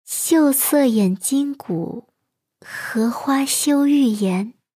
girl_02.mp3